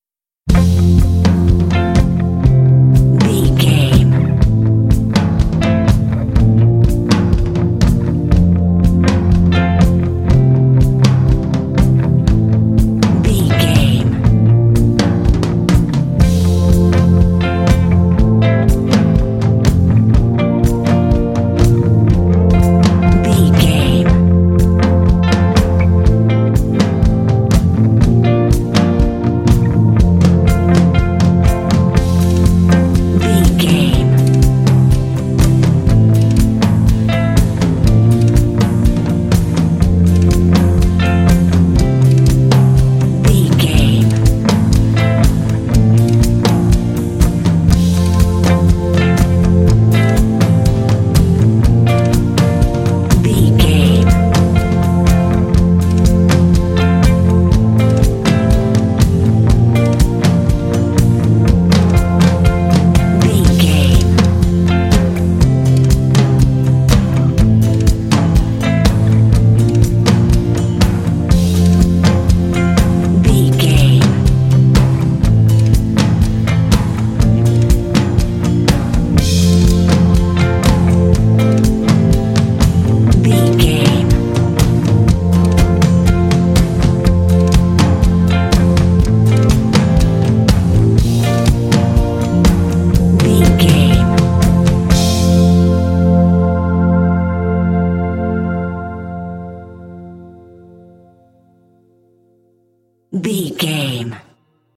Uplifting
Ionian/Major
cool
smooth
calm
drums
electric guitar
bass guitar
synthesiser
indie
alternative rock
contemporary underscore